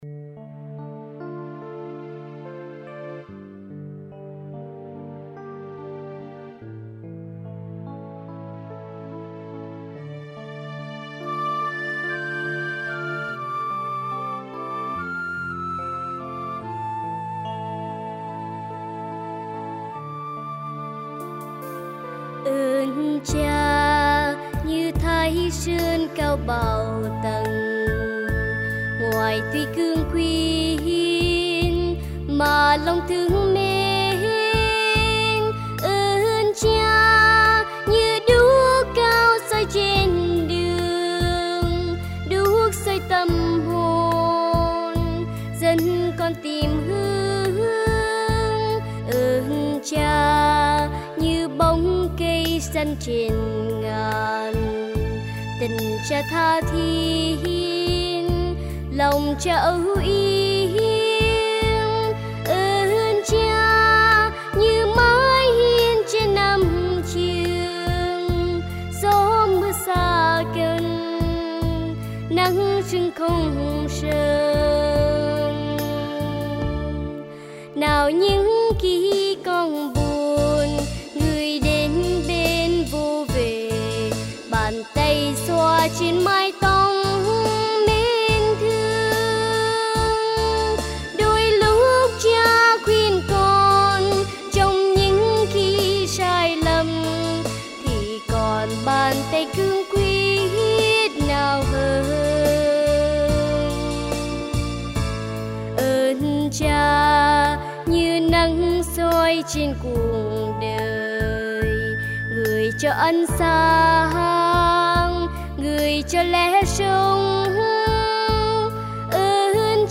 Dơn ca